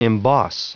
Prononciation du mot emboss en anglais (fichier audio)
Prononciation du mot : emboss